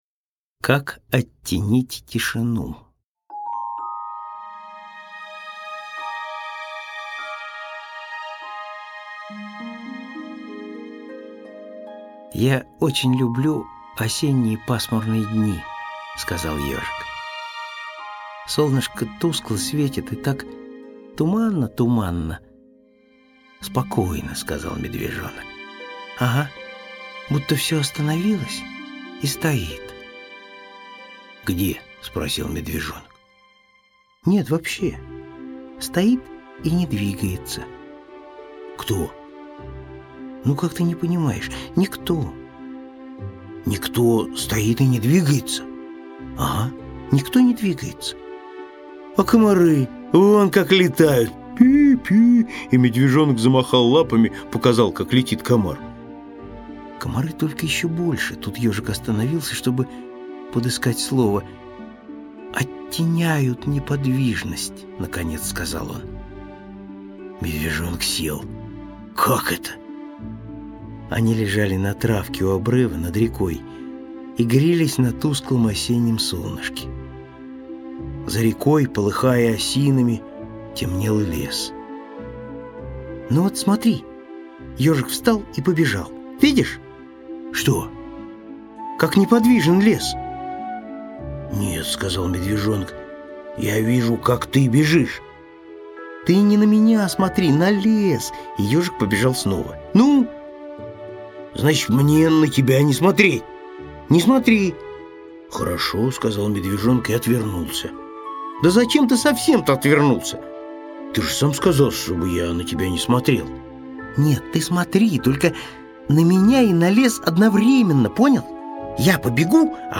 Как оттенить тишину – Козлов С.Г. (аудиоверсия)